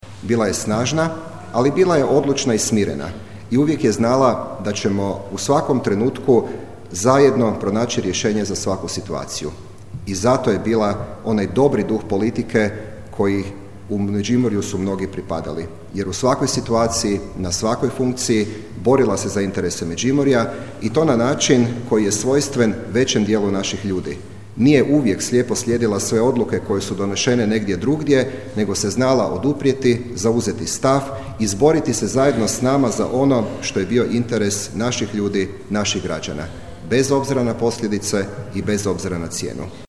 Skupština Međimurske županije održala je komemorativnu sjednicu u sjećanje na aktualnu županijsku vijećnicu i bivšu saborsku zastupnicu Bernardu Topolko (65).
Župan Matija Posavec: